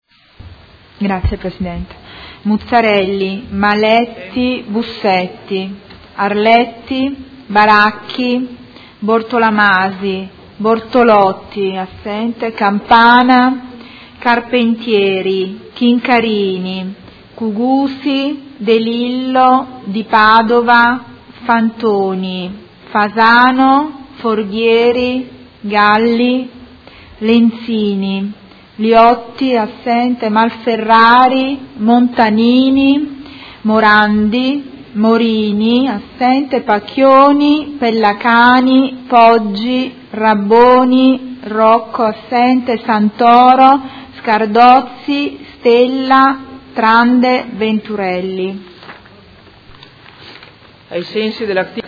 Seduta del 2/2/2017. Appello
Segretario Generale